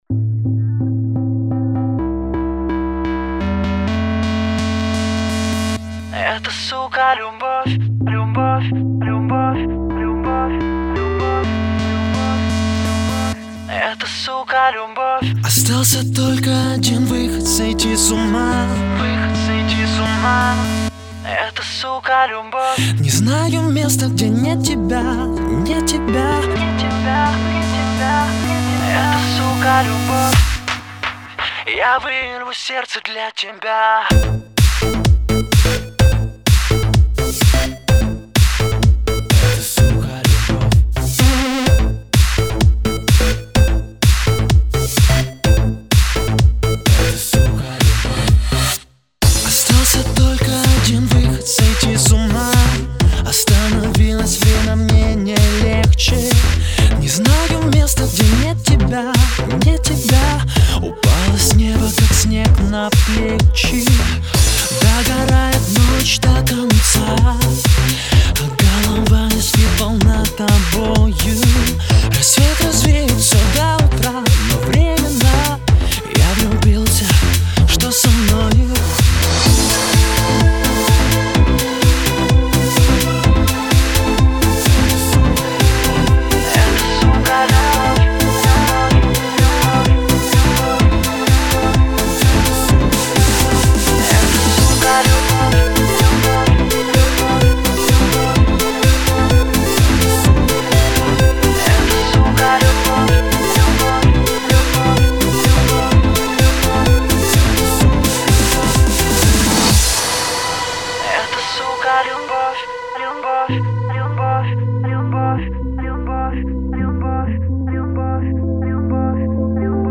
Категория: Electro